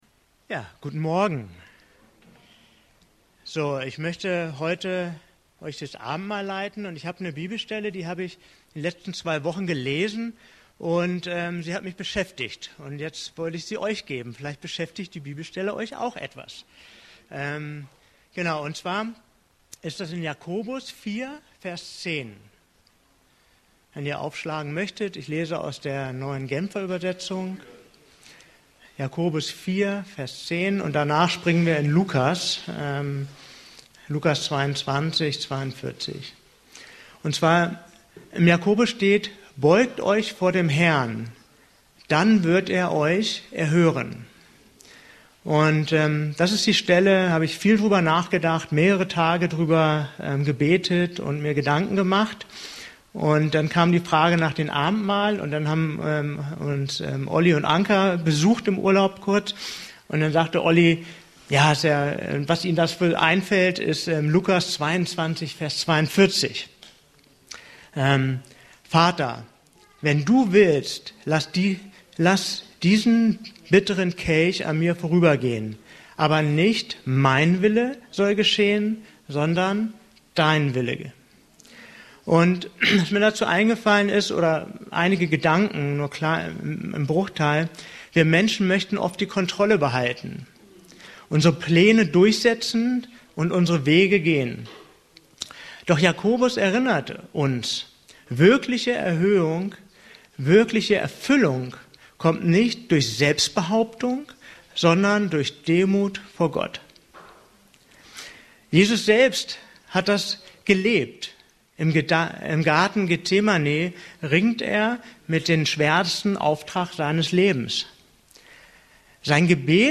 E-Mail Details Predigtserie: Abendmahl Datum